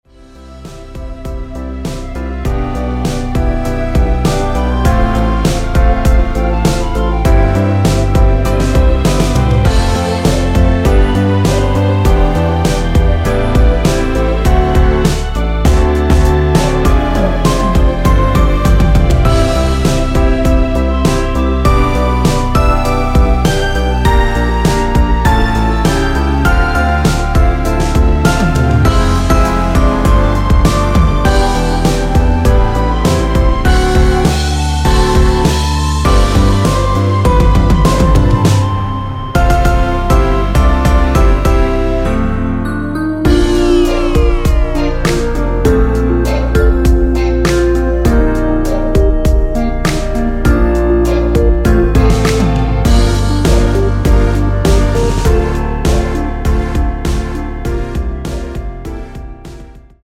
원키에서(-1)내린 멜로디 포함된 MR입니다.(미리듣기 확인)
Bb
앞부분30초, 뒷부분30초씩 편집해서 올려 드리고 있습니다.
중간에 음이 끈어지고 다시 나오는 이유는